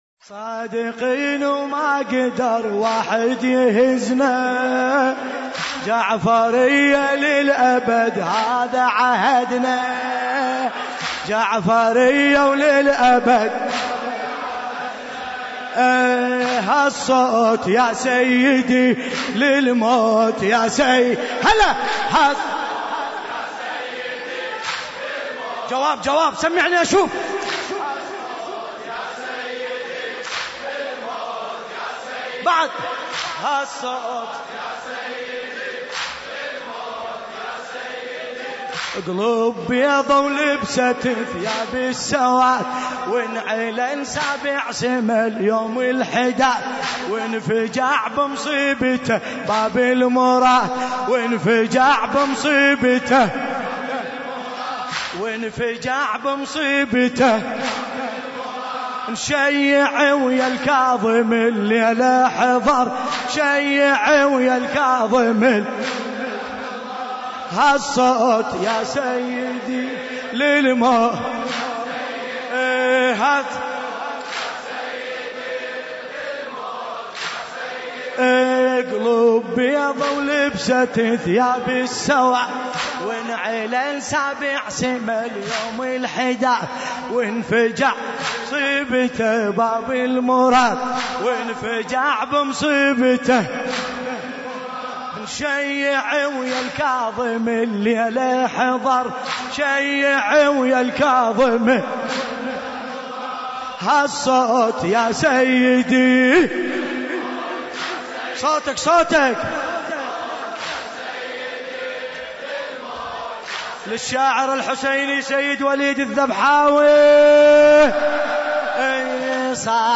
این مراسم با مداحی : حاج باسم الكربلائي در حسینیه أميرالمؤمنين (ع) تهران برگزار شد